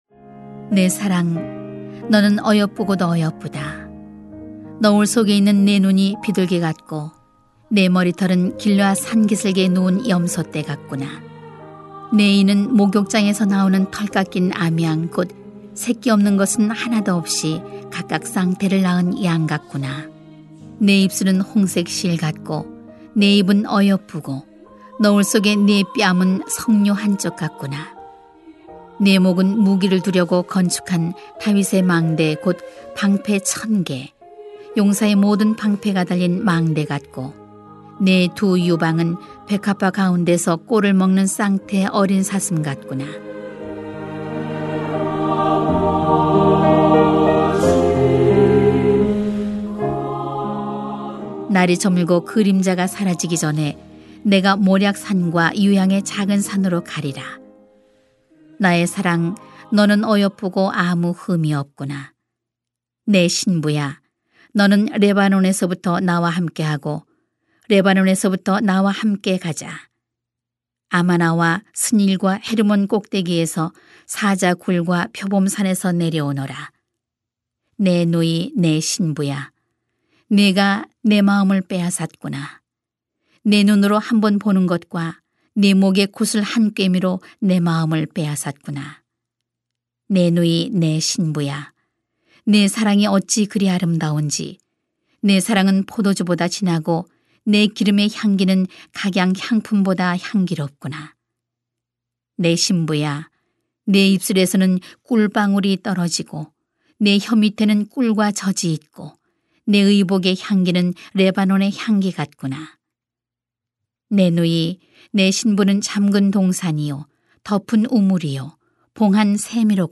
[아 5:1-11] 기쁨의 동산을 가꾸는 신앙 > 새벽기도회 | 전주제자교회